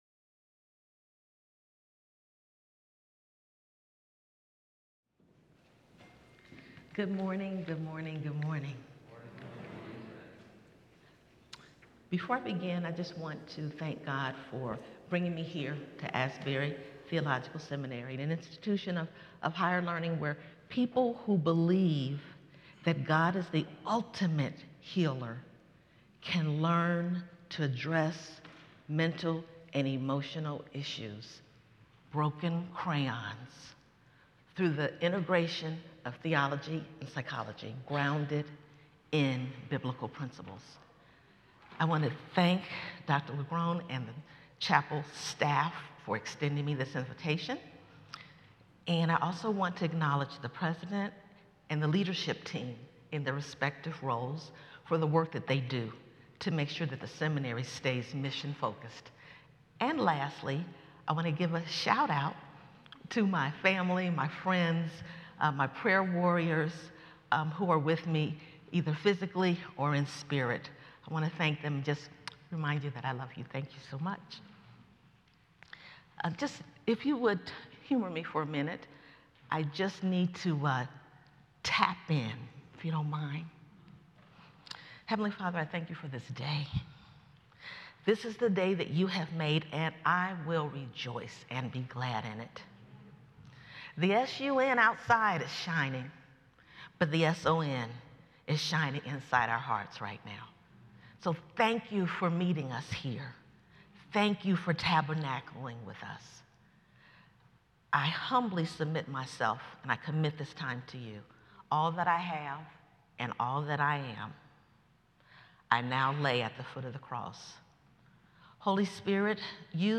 The following service took place on Wednesday, October 30, 2024.